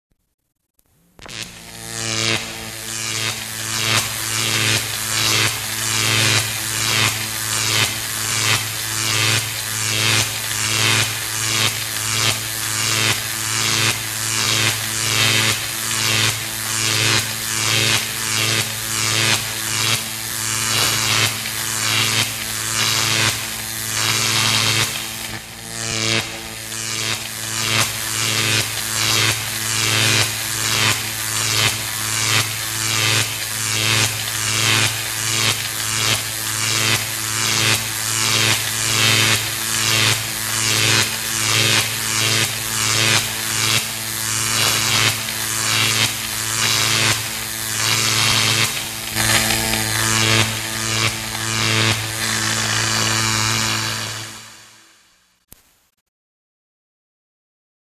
CORTO CIRCUITO
Tonos gratis para tu telefono – NUEVOS EFECTOS DE SONIDO DE AMBIENTE de CORTO CIRCUITO
Ambient sound effects
corto_circuito.mp3